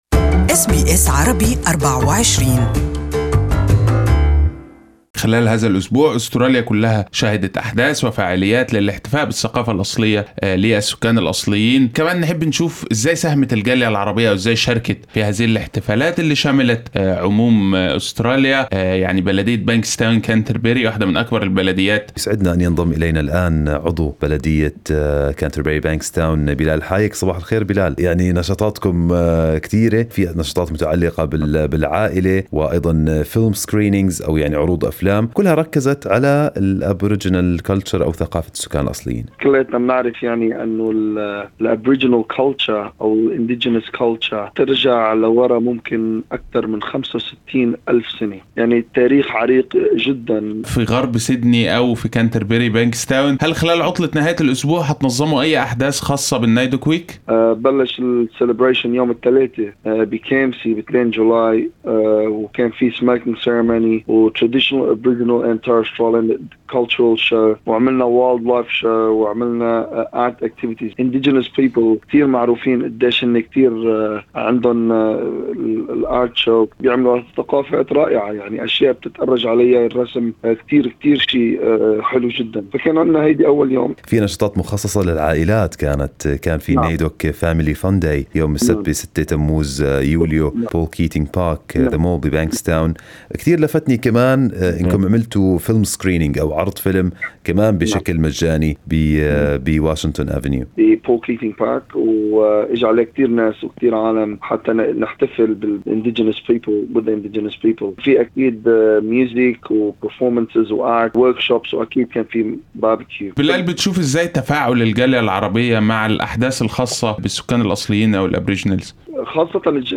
عضو بلدية كانتبري بانكستاون يتحدث عن الأنشطة التي نظمتها البلدية بمناسبة أسبوع الاحتفال بثقافة السكان الأصليين.